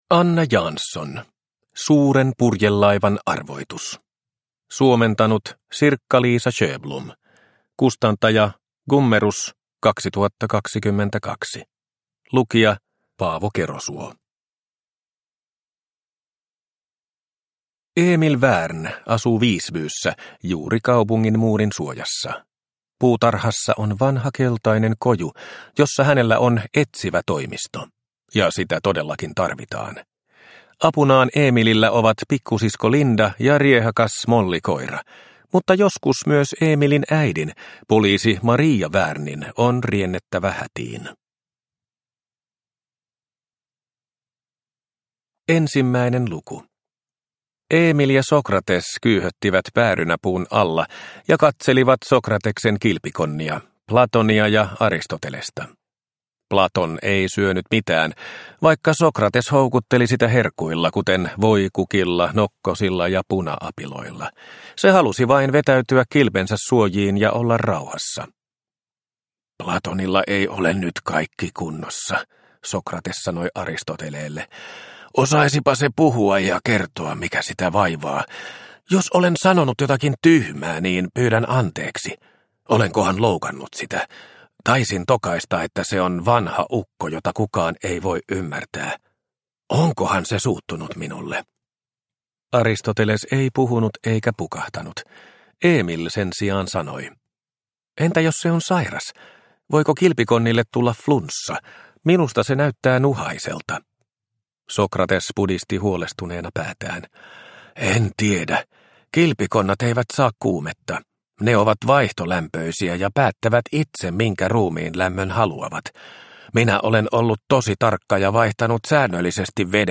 Suuren purjelaivan arvoitus – Ljudbok – Laddas ner